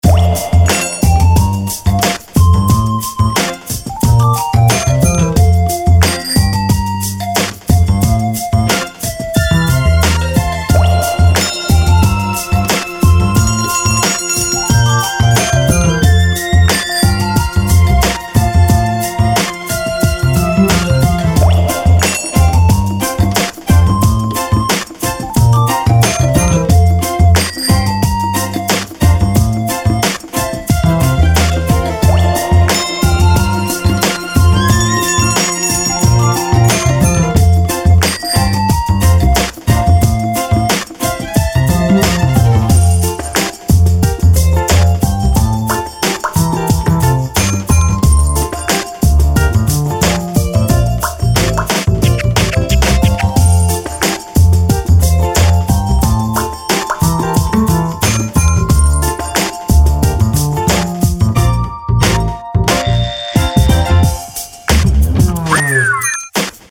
猫の散歩をイメージした楽曲で、街中をのんびり散策するようなシーンに向いた楽曲です。
マッタリ・コミカルな癒し系のBGMになっています。